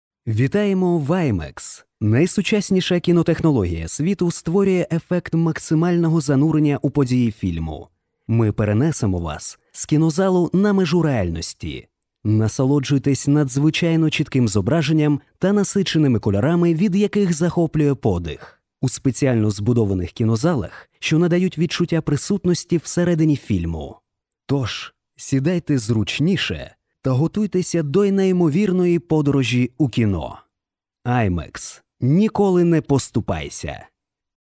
Озвучка имиджевого ролика для кинотеатров IMAX в Украине